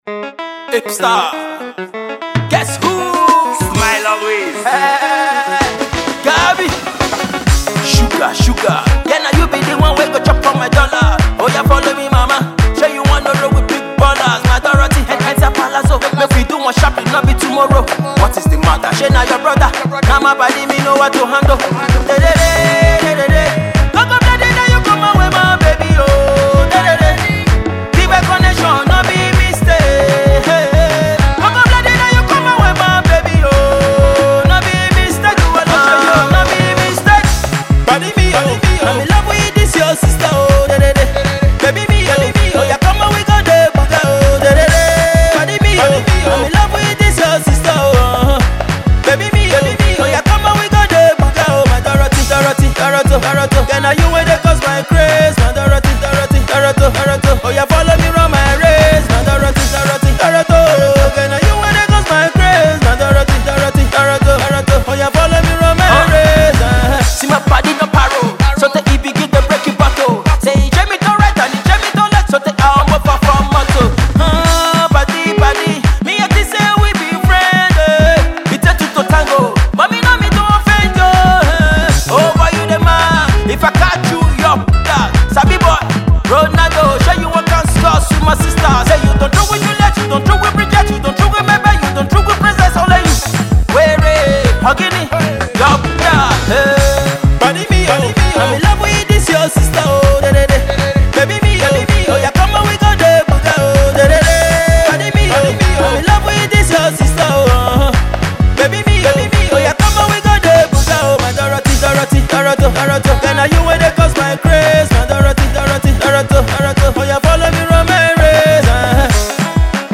Alternative Pop